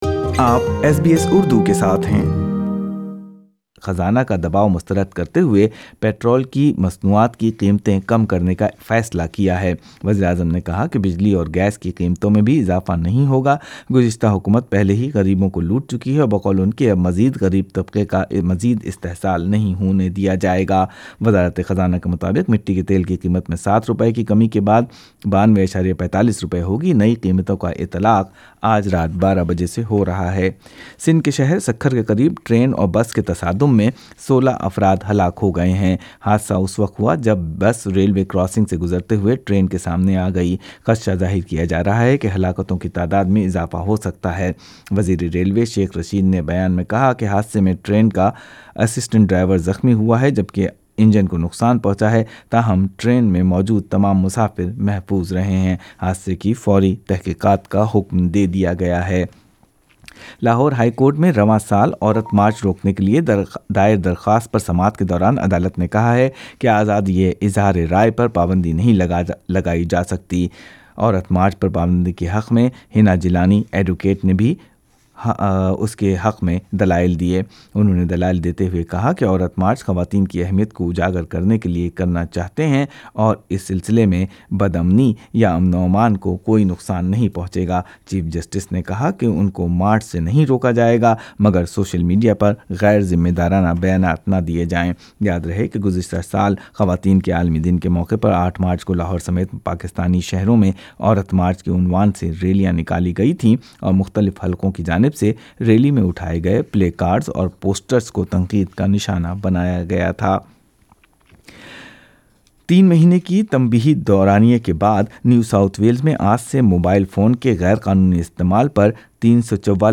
ایس بی ایس اردو خبریں ۲ مارچ ۲۰۲۰